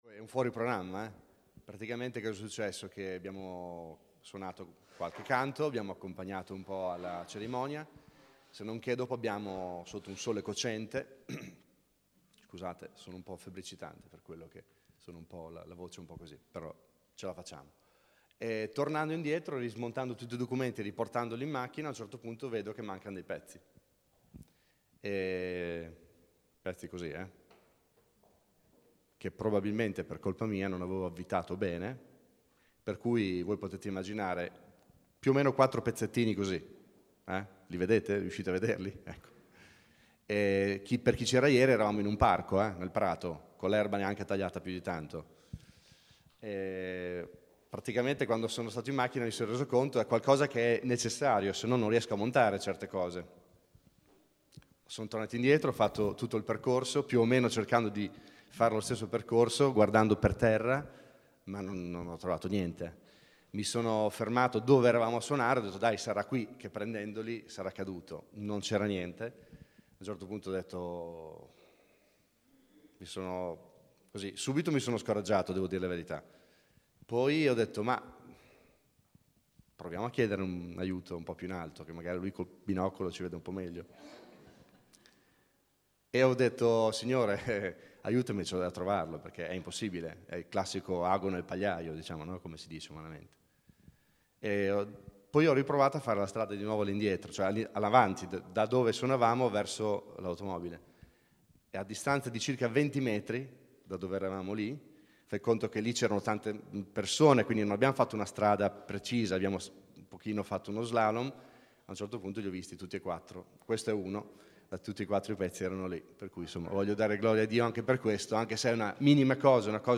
Buon ascolto! 0:00 ( Clicca qui se vuoi scaricare il file mp3 ) ‹ TDR 2014 Numero 8 Disciplina spirituale › Pubblicato in Messaggio domenicale